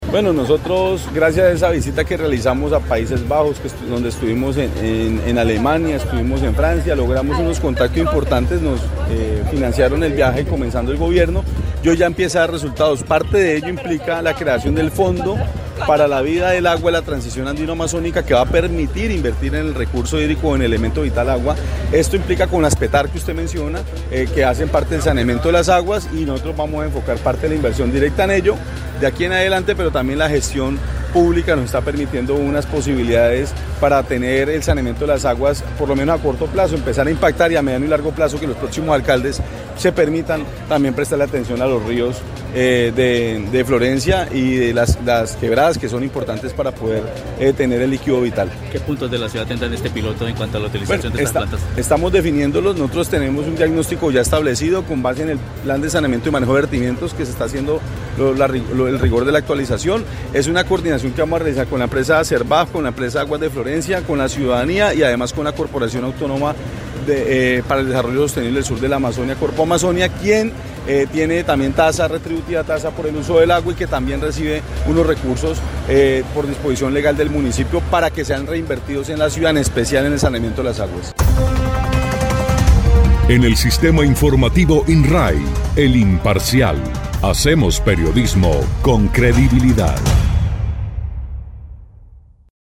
01_ALCALDE_MONSALVE_ASCANIO_PTAR.mp3